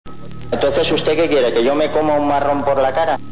Durante el juicio oral de 1997 (1)